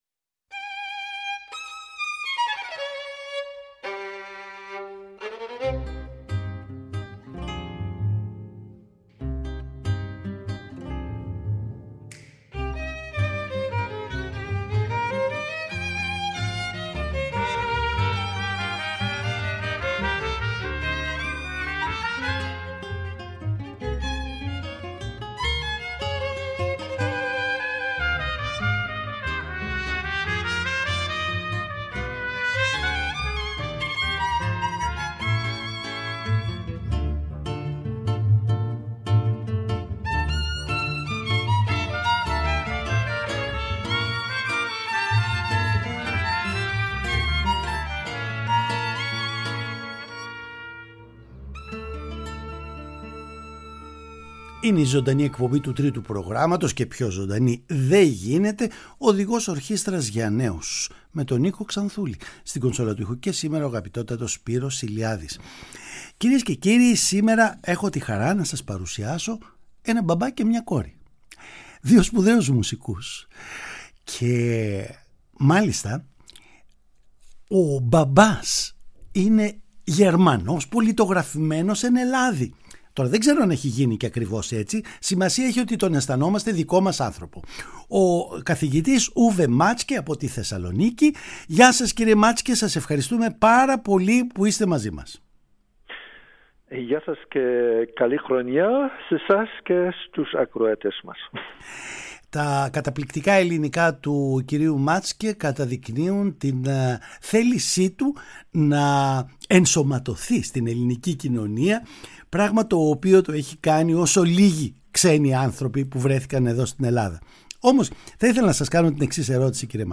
Δύο νέες ηχογραφήσεις προστέθηκαν πρόσφατα στην πλούσια αρχειοθήκη της ΕΡΤ και ειδικότερα του Τρίτου Προγράμματος. Η σονάτα για βιολί και πιάνο του Σεζάρ Φρανκ καθώς και η Σουίτα πάνω σε λαϊκές μελωδίες των Δωδεκανήσων του Γιάννη Κωνσταντινίδη. Αμφότερα τα έργα παιγμένα από τη μια με δεξιοτεχνία ζηλευτή και από την άλλη με μια μουσικότητα εξαιρετική, θα ακουστούν στην εκπομπή μας και θα συζητήσουμε με τους σπουδαίους μουσικούς που τα ηχογράφησαν.